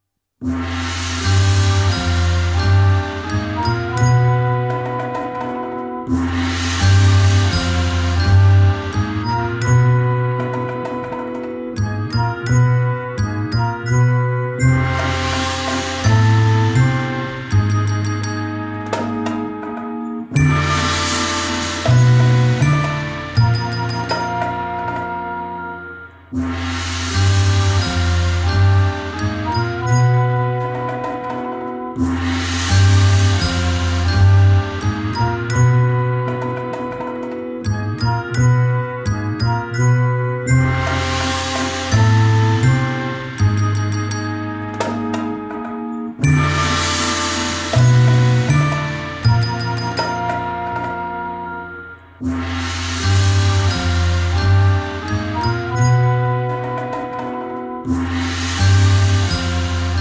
Italian library music